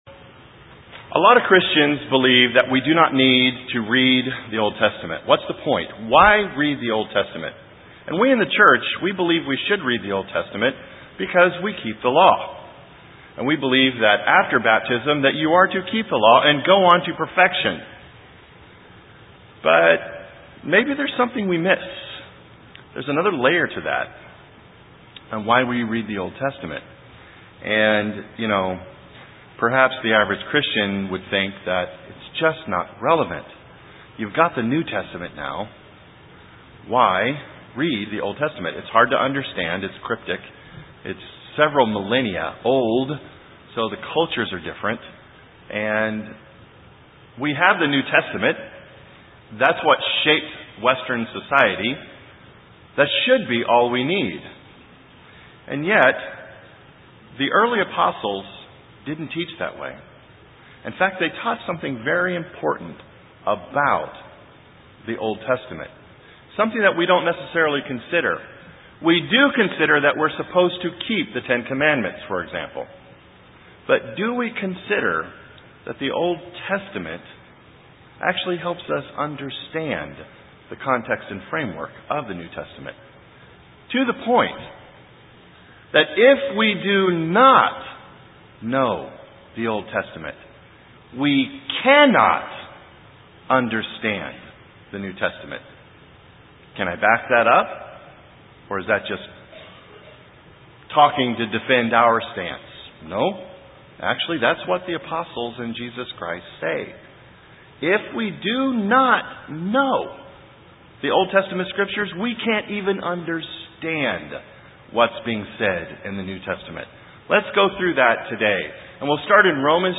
This sermon will prove that we need to understand a study the Old Testament.